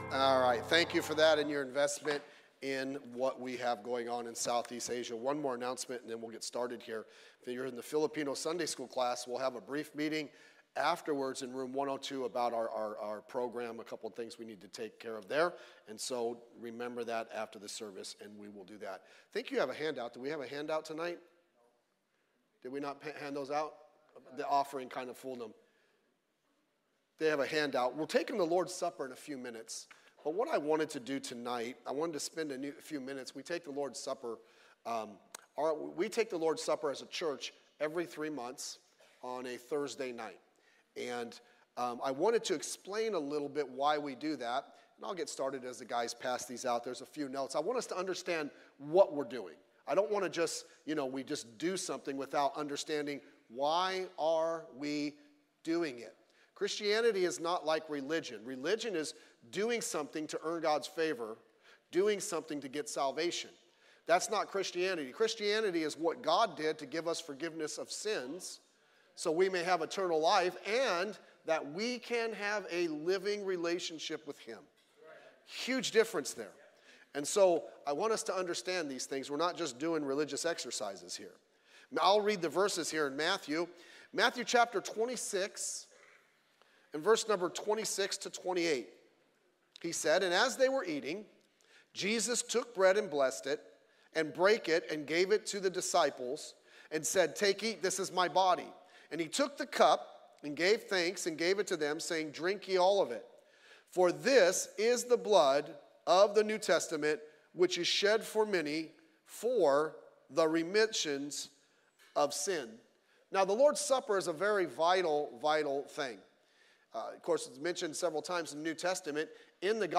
Pacific Baptist Podcast features the practical preaching